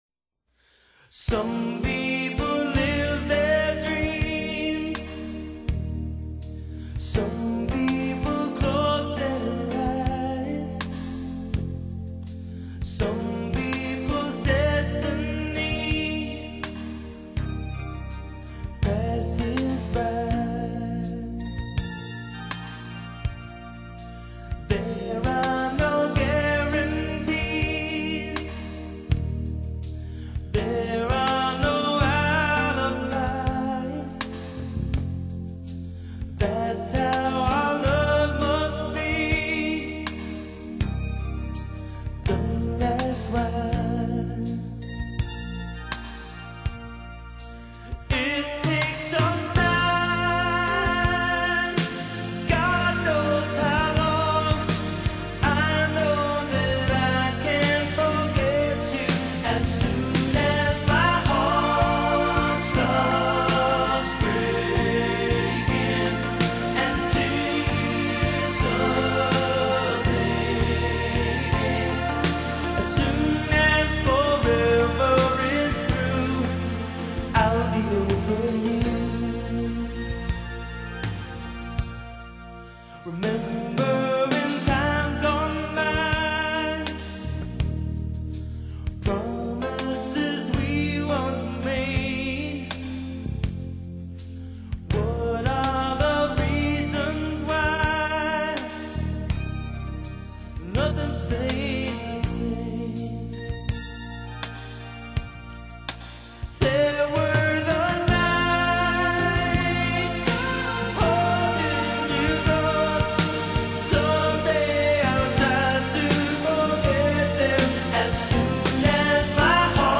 裡面收錄都是經典的原唱版的情歌